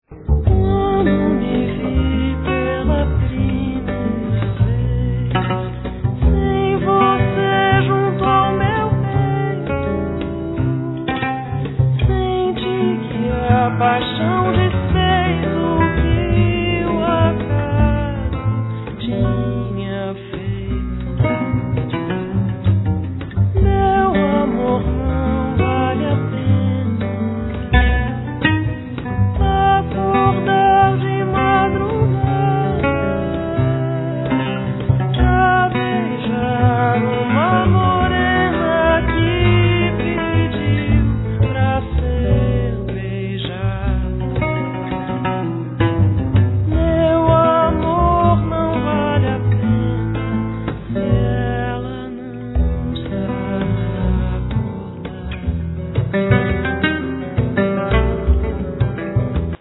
Guitar
Bandolim
Double bass